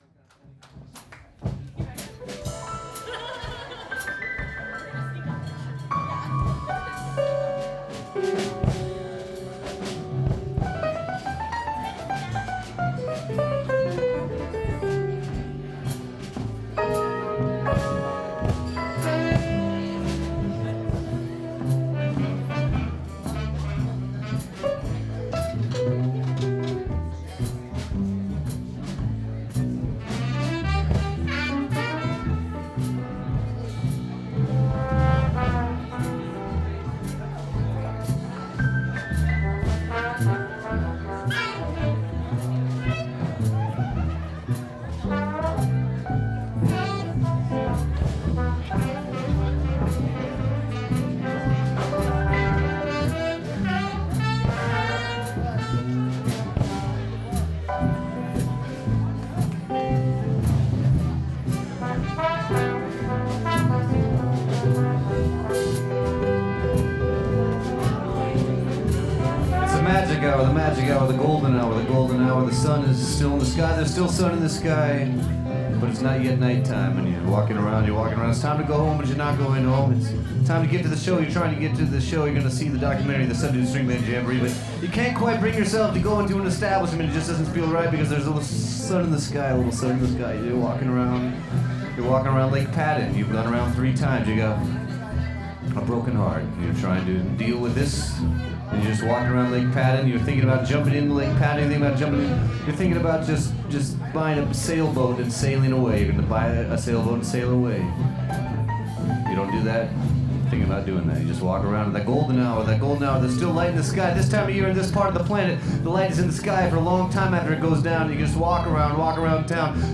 Jazz, Beat Poetry, Working Blues, Funk and a lot of Cool.